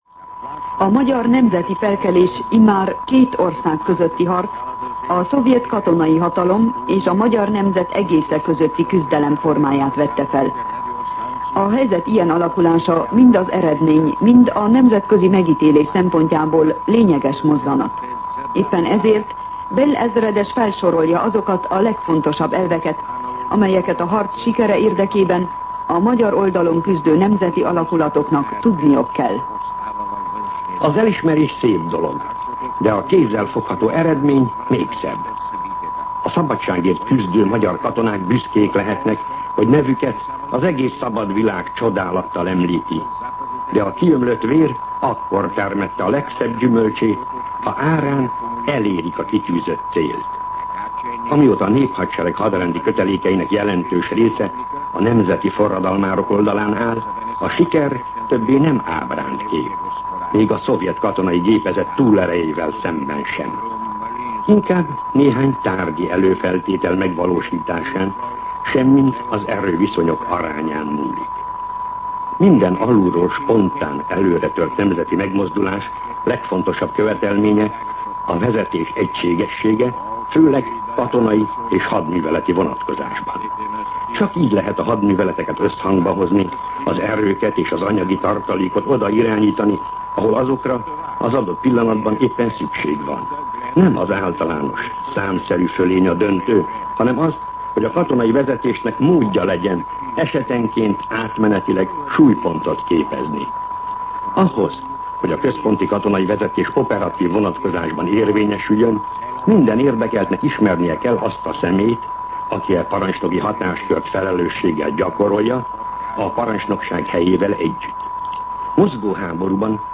Katonapolitikai kommentár